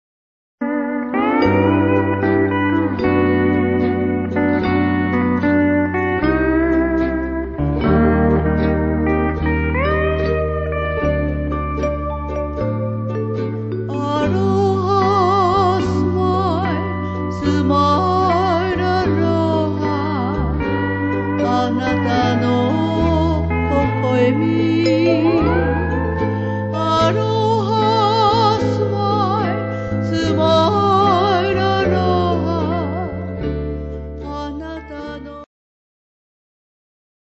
Steel Guitar
Guitar
Ukulele
Keyboards
Bass